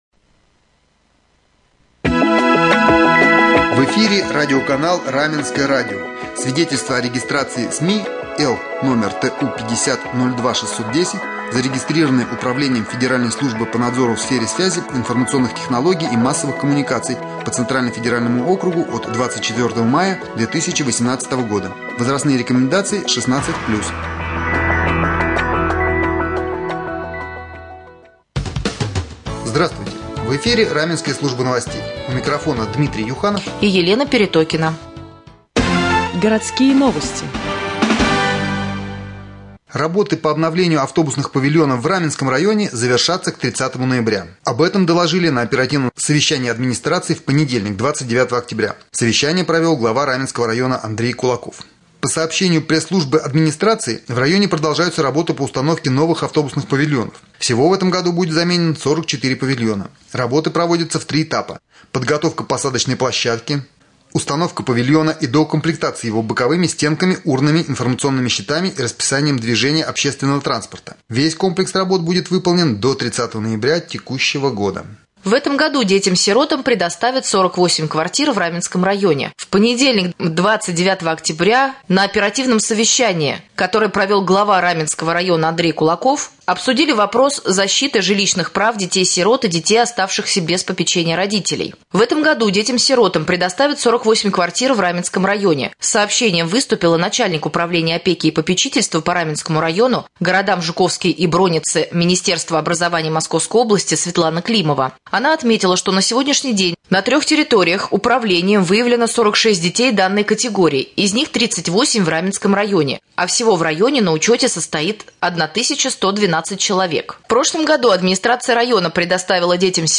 Узнаете из прямого эфира